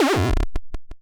gameover_sfx.wav